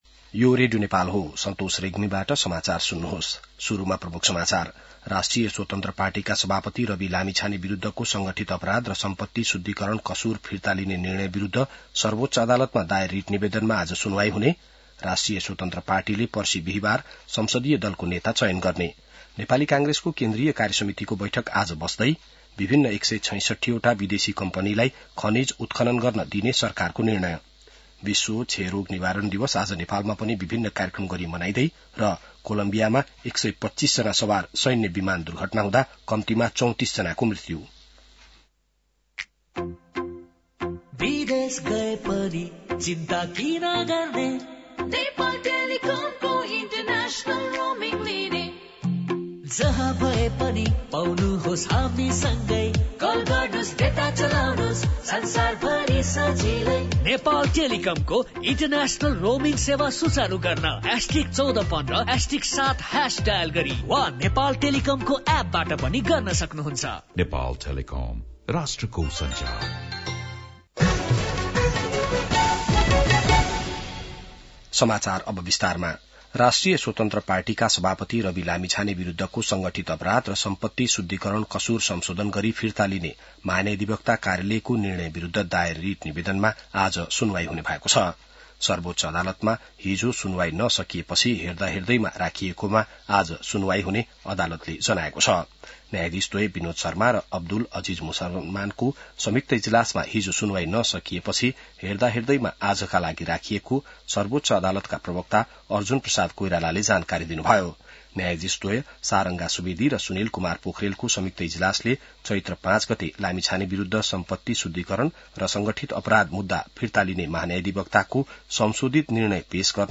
बिहान ७ बजेको नेपाली समाचार : १० चैत , २०८२